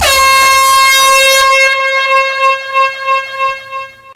DJ-Air-Horn
DJ-Air-Horn.mp3